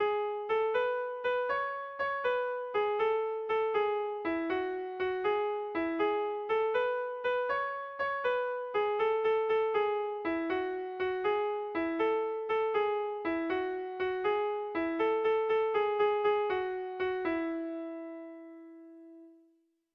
Bertso melodies - View details   To know more about this section
Kontakizunezkoa
ABABBB